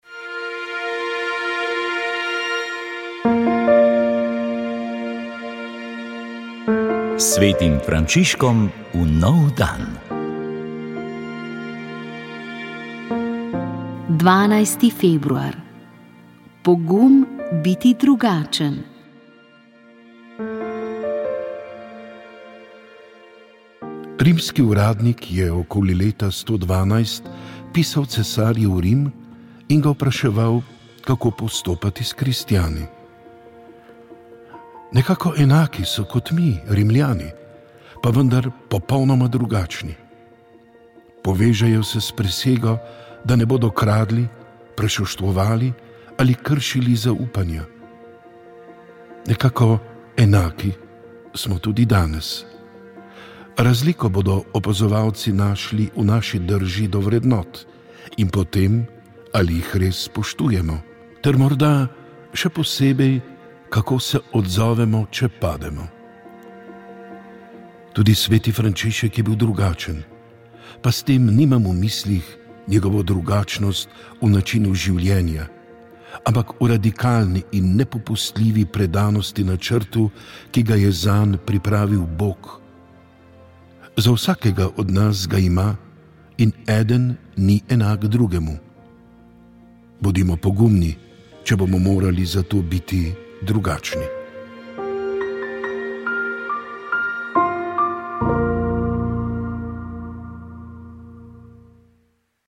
pričevanje 3. del